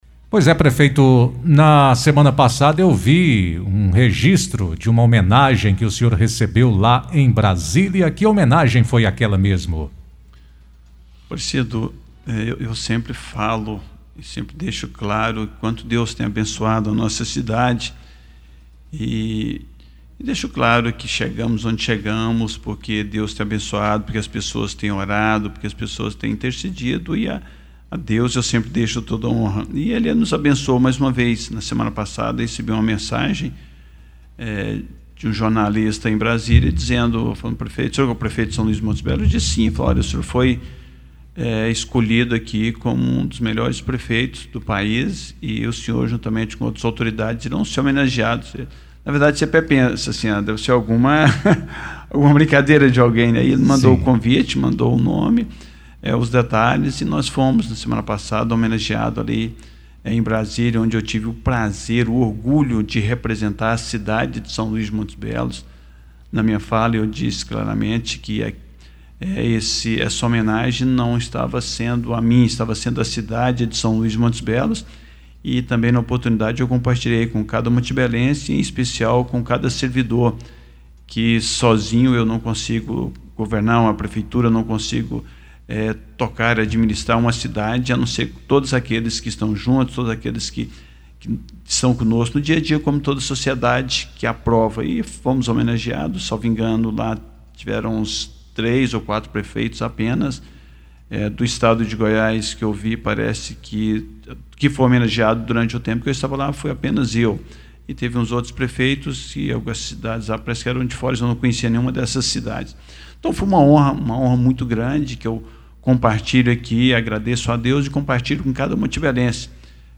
Além da criação da Patrulha Escolar, a prefeitura de São Luís de Montes Belos está trabalhando a instalação de equipamentos e de concertinas nos muros das escolas para impedir invasões. O prefeito, Major Eldecírio da Silva, falou sobre o assunto em entrevista exclusiva ao Jornal RDR. Ele comentou também sobre a chamada pública para a destinação de terrenos públicos para empresas e esclareceu a polêmica envolvendo a COMIGO, que se instalou em Firminópolis.
Major Eldecírio ainda respondeu perguntas de ouvintes e comentou a homenagem que ele recebeu em Brasília.